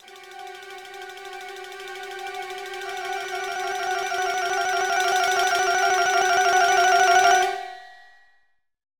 Countdown music